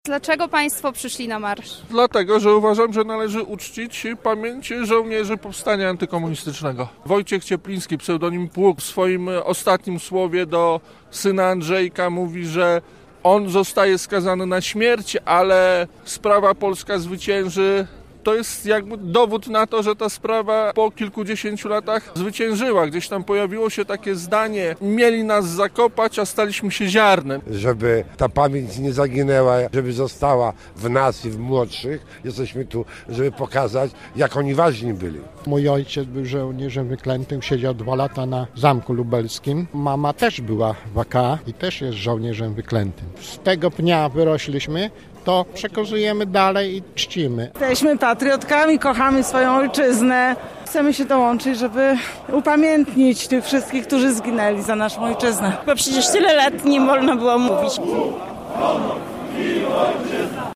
Marsz Pamięci Żołnierzy Wykletych news
Marsz-Pamięci-Żołnierzy-Wykletych-news-.mp3